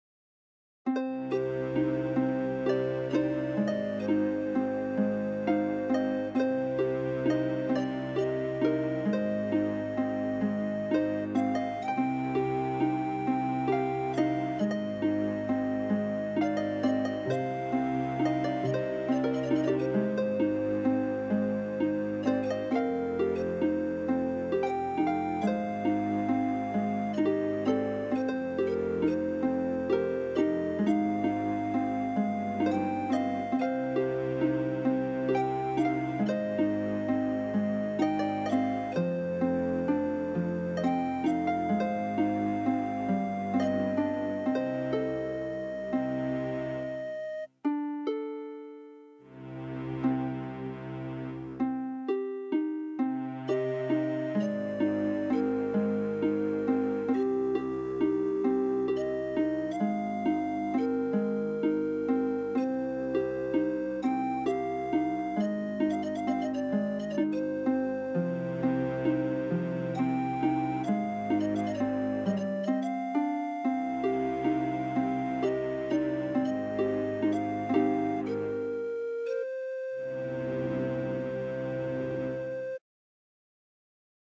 Pan Flute With Short Flute Melody
panflutewithmelodyshort_0.ogg